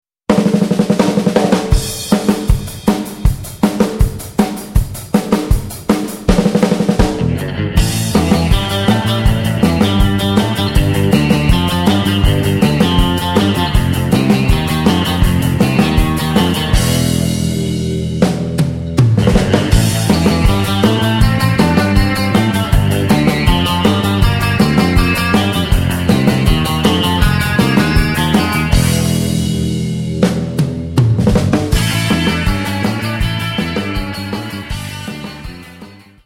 surf, hotrod, space, and spaghetti western tunes